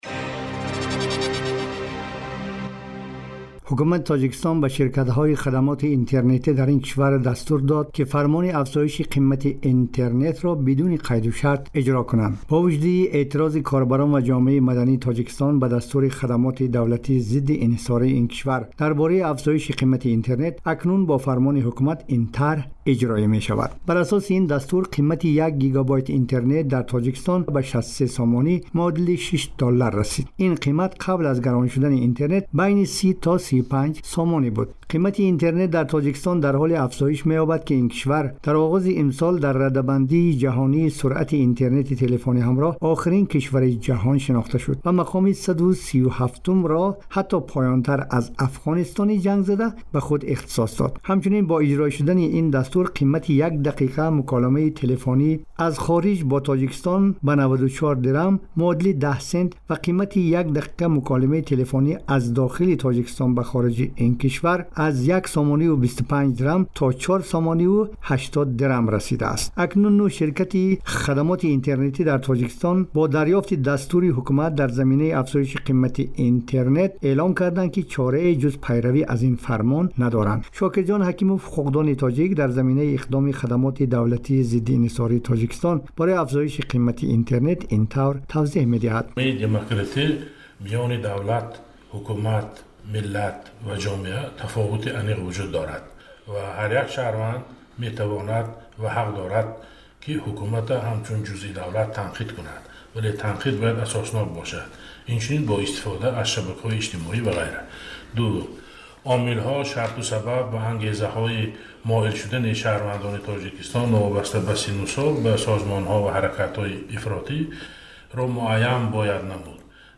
Вокунишҳо ба афзоиши қимати интернет дар Тоҷикистон (гузориши вижа)